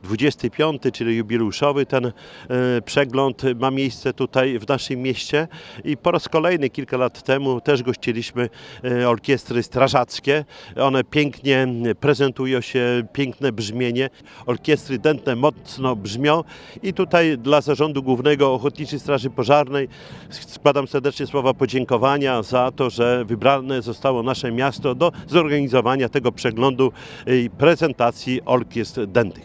XXV Regionalny Przegląd Orkiestr Ochotniczych Straży Pożarnych odbył się w niedzielę (17.06) w Suwałkach.
Podziękowania dla Ochotniczej Straży Pożarnej złożył Czesław Renkiewicz, prezydent Suwałk.